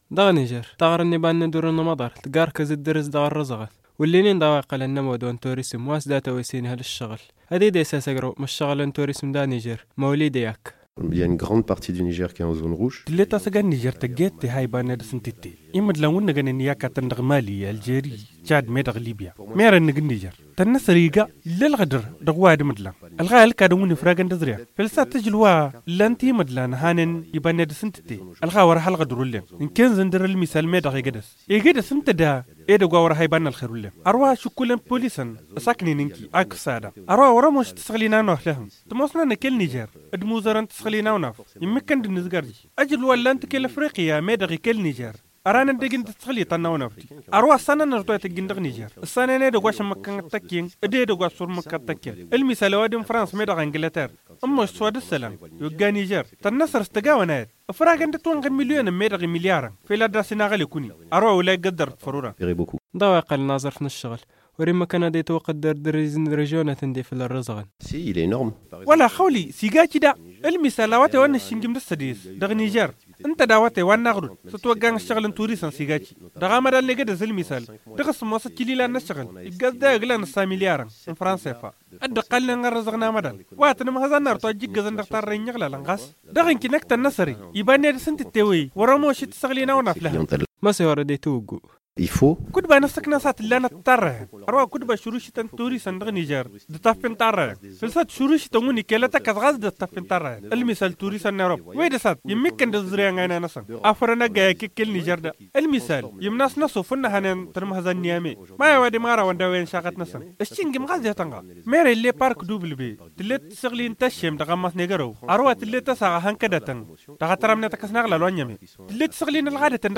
[Magazine] Promouvoir le tourisme interne pour pallier le manque à gagner du tourisme externe - Studio Kalangou - Au rythme du Niger